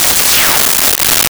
Lasers10
Lasers10.wav